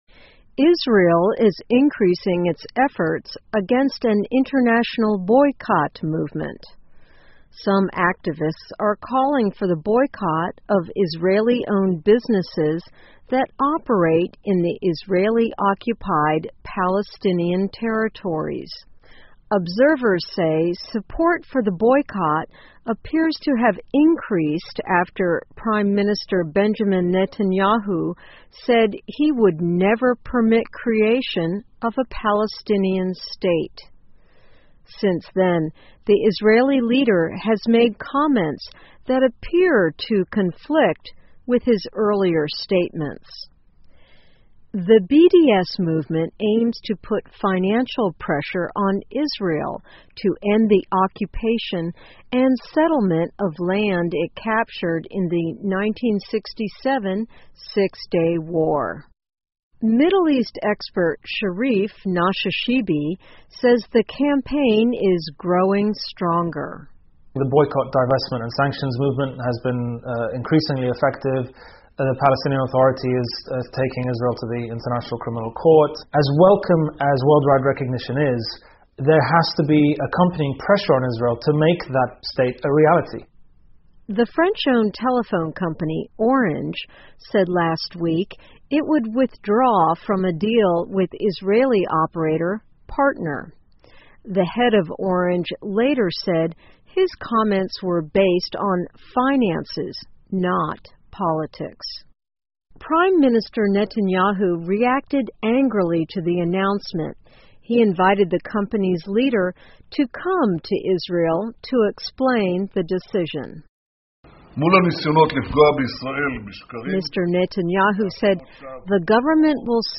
VOA慢速英语2015 以色列抵制联合运动 听力文件下载—在线英语听力室